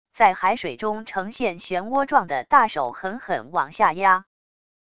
在海水中呈现漩涡状的大手狠狠往下压wav音频